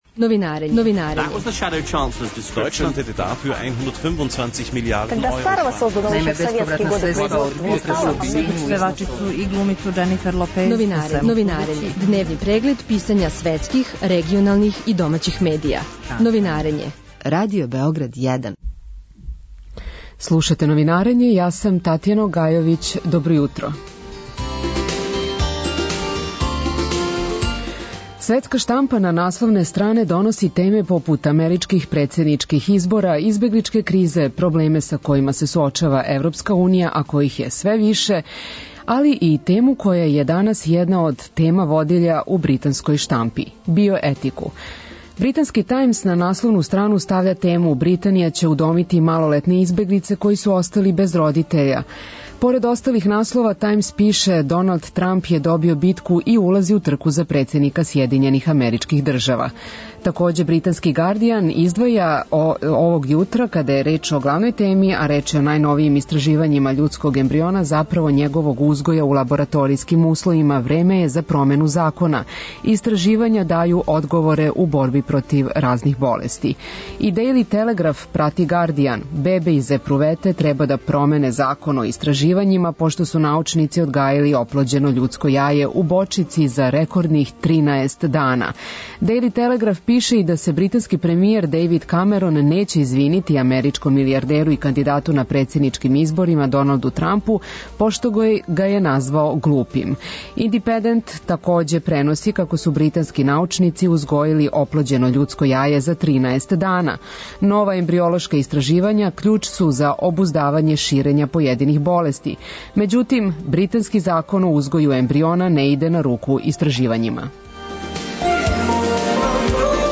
разговара